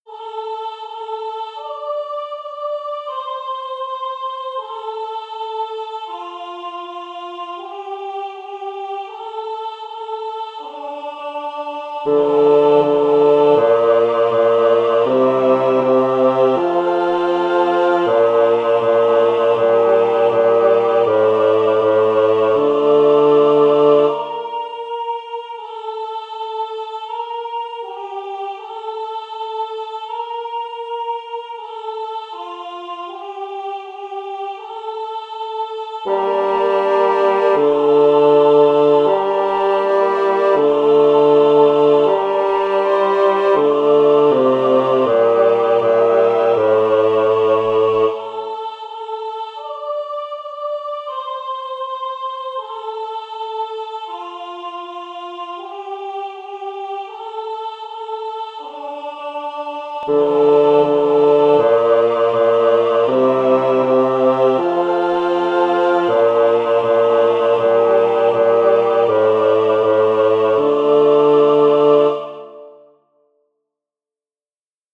Por un lado, los queridos/odiados MIDIS de cada voz.